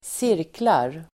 Uttal: [²s'ir:klar]